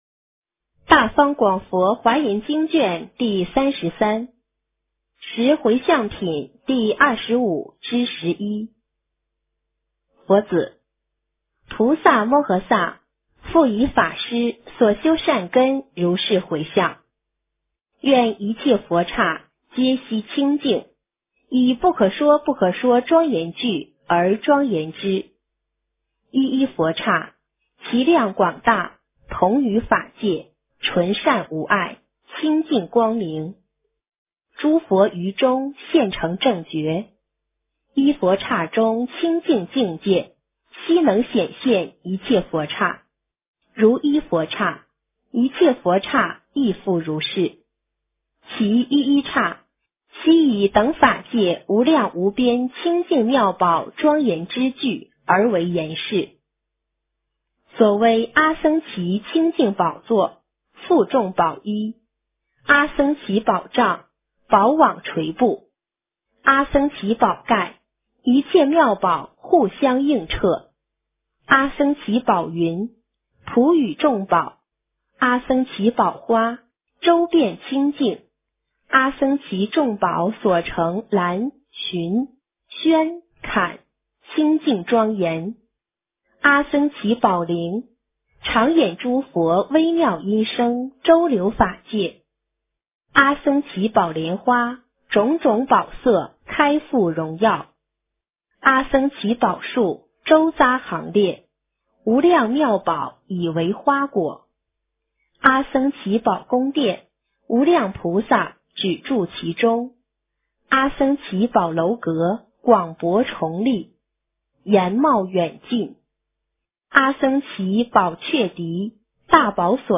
华严经33 - 诵经 - 云佛论坛